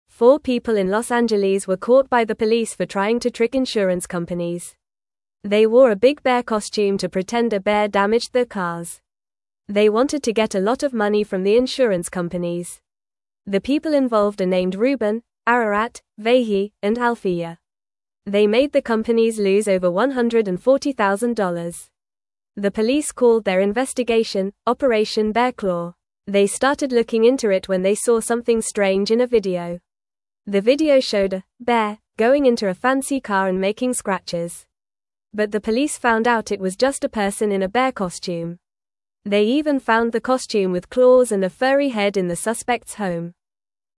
Fast
English-Newsroom-Beginner-FAST-Reading-People-Pretend-Bear-Damaged-Cars-for-Money.mp3